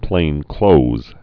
(plānklōz, -klōthz)